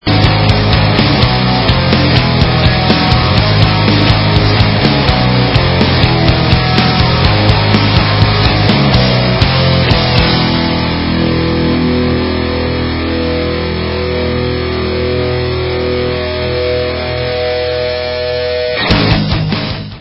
Dark, yet melodic hardcore